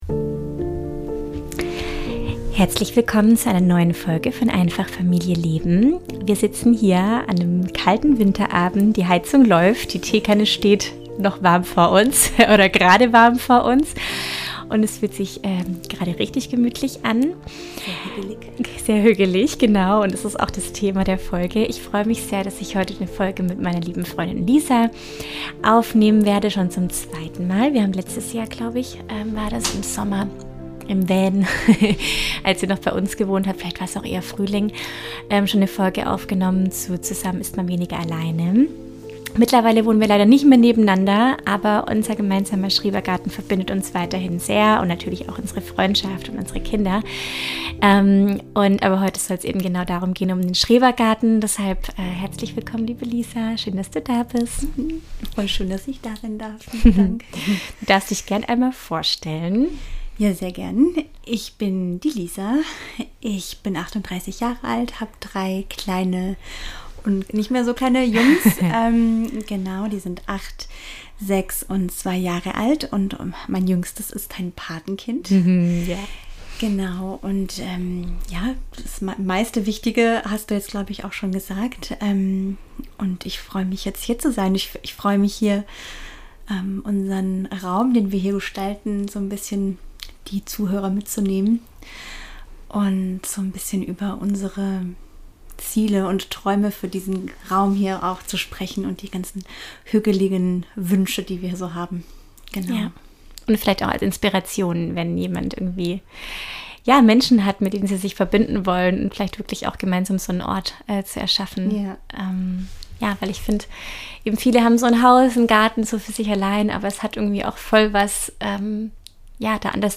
Beschreibung vor 4 Monaten In dieser Folge von Einfach Familie leben nehmen meine Freundin und ich dich mit in unseren Schrebergarten – an einem ruhigen Winterabend, mit Tee, Wärme und ganz viel Hygge. Wir sprechen darüber, wie unser Garten über das Jahr zu einem echten Rückzugsort geworden ist: für zwei Familien, für kleine Rituale, kreative Abende in der Gartenhütte und stille Momente der Verbundenheit.